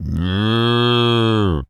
pgs/Assets/Audio/Animal_Impersonations/cow_moo_01.wav at master
cow_moo_01.wav